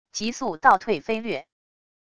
极速倒退飞掠wav音频